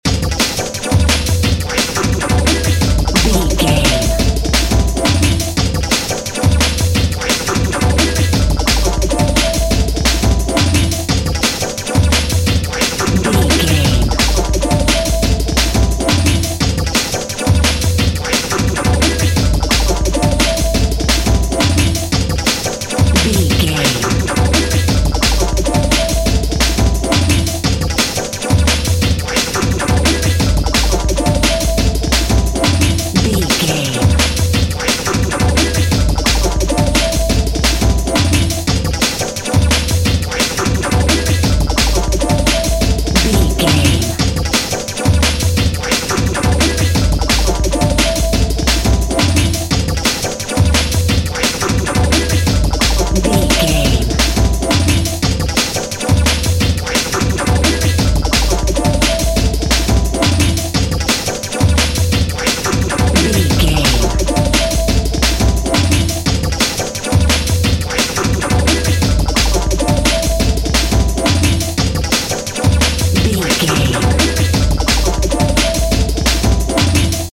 Atonal
Fast
futuristic
hypnotic
industrial
mechanical
dreamy
frantic
drum machine
synthesiser
break beat
sub bass
synth lead
synth bass